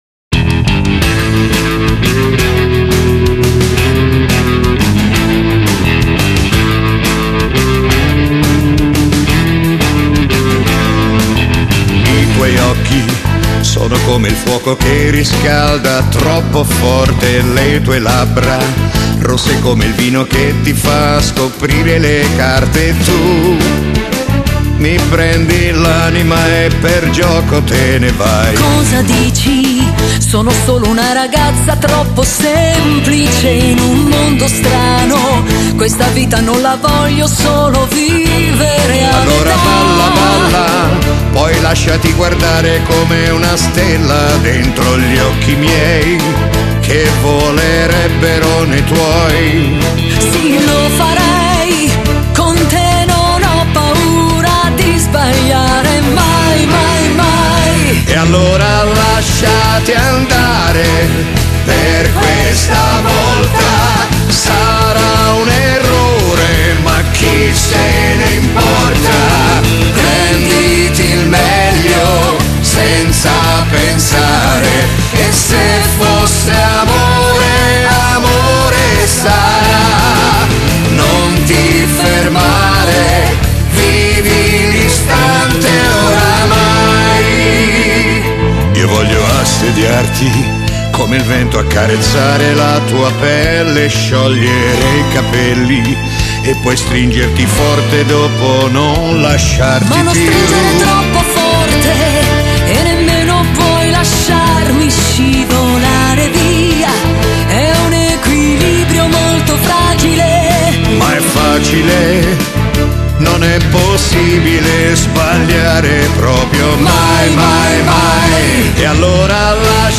Genere: Rumba rock